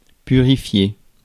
Ääntäminen
IPA: /py.ʁi.fje/